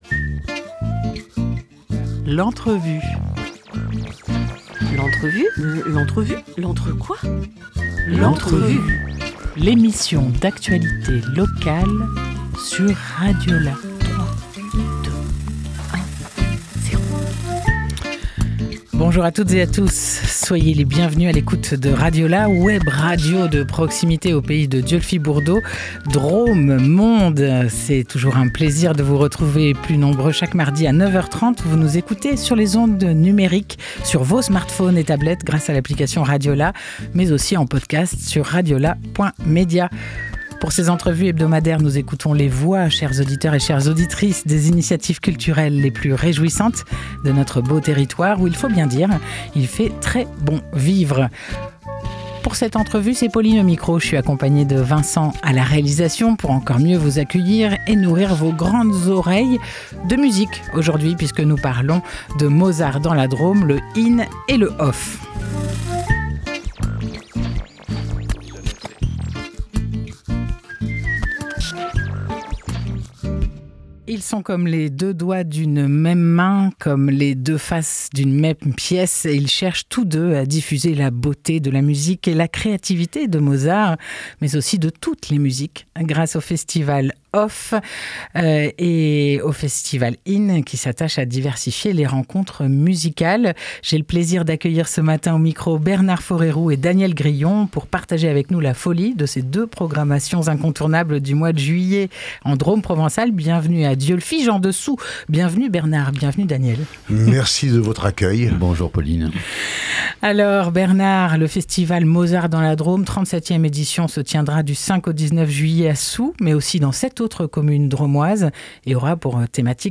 21 avril 2026 14:27 | Interview